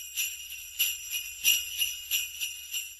disengage.wav